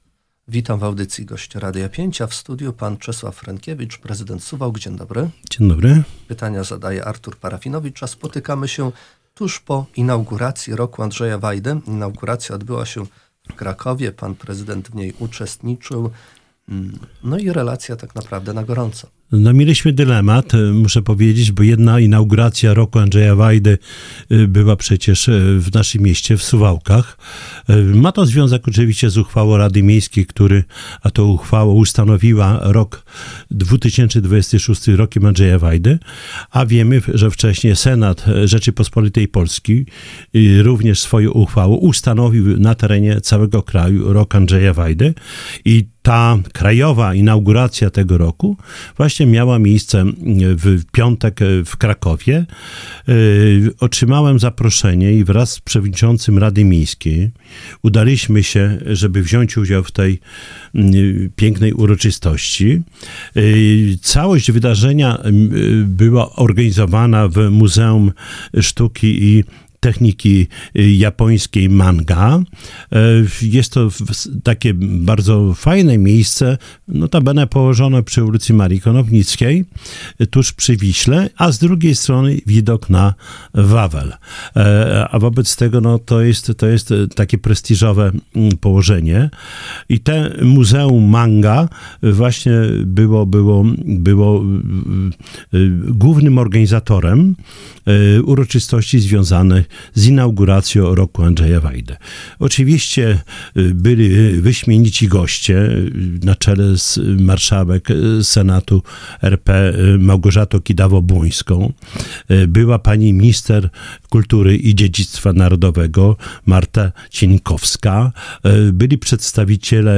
O inauguracji mówił w Radiu 5 Czesław Renkiewicz, prezydent Suwałk.